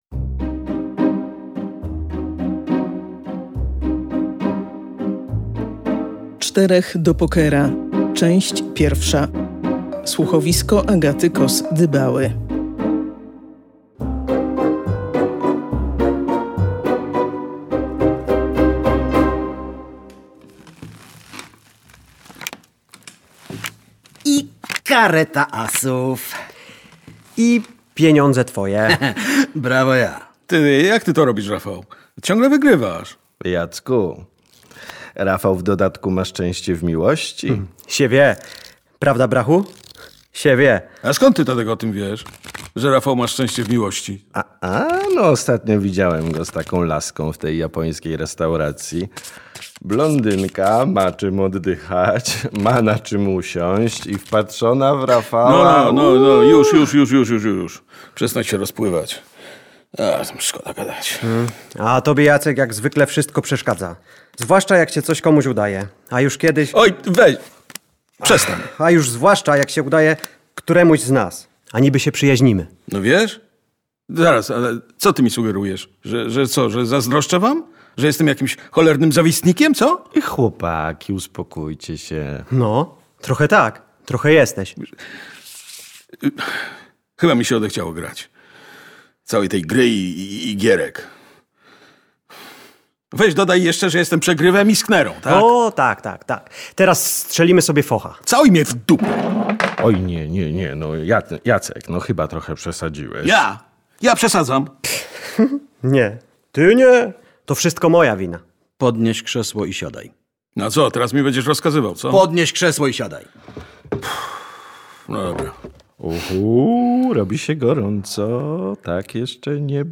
Wtedy czterej bohaterowie nowej, kryminalnej opowieści zaczynają stawiać przed sobą zupełnie inne wyzwania. „Czterech do pokera” to słuchowisko, które będziemy prezentować w lipcowe czwartkowe wieczory.